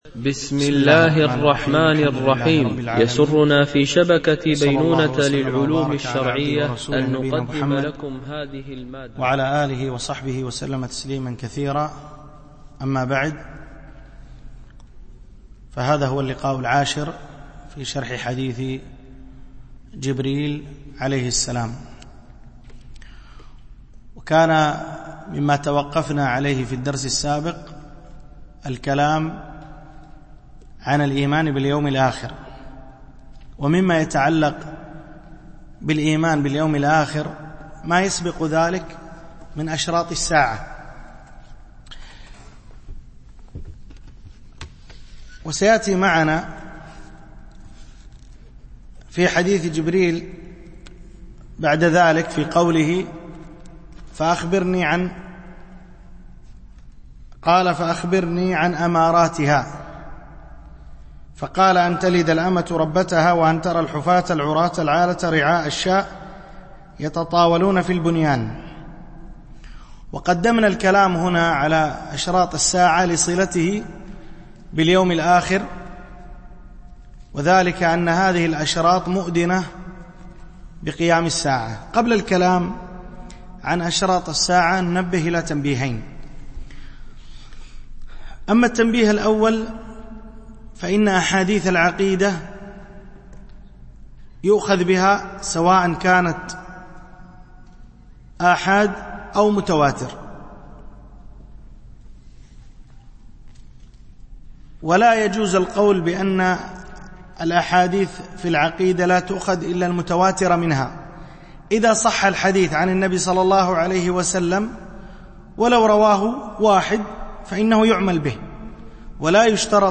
شرح حديث جبريل في بيان مراتب الدين - الدرس 10